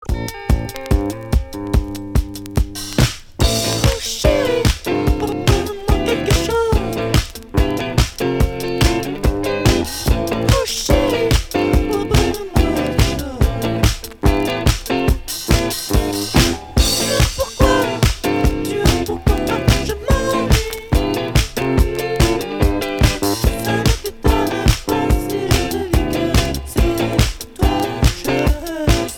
New wave Premier 45t retour à l'accueil